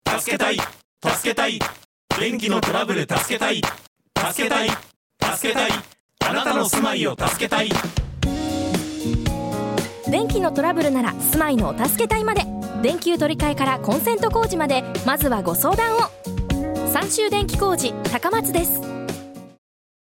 ラジオCM放送中！！ 毎週月曜～金曜 FM香川 朝8:25頃放送 ラジオCMを聞く